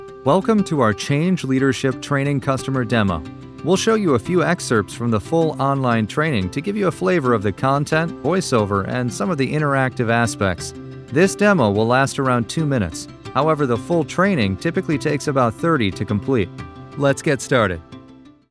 Male
My voice has been described as honest, warm, soothing, articulate, relatable, sincere, natural, conversational, friendly, powerful, intelligent and "the guy next door."
E-Learning